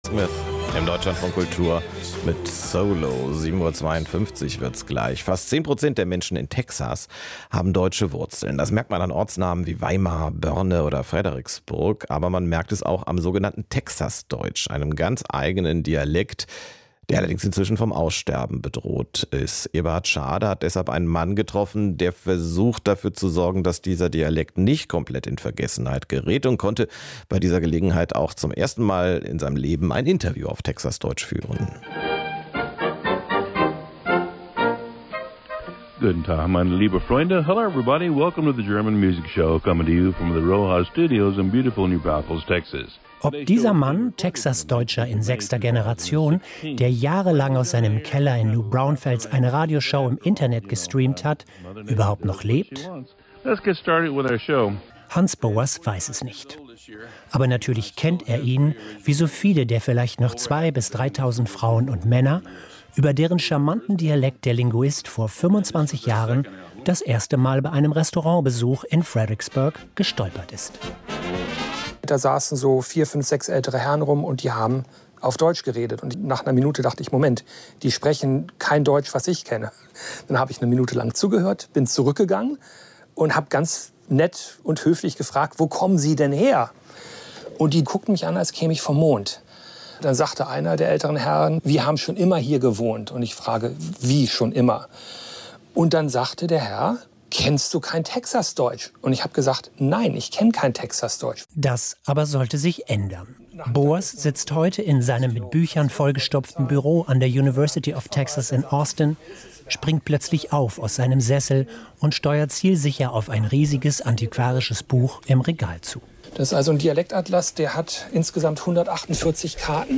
This week, an interview with me about Texas German aired on the “Deutschlandfunk” (German National Public Radio).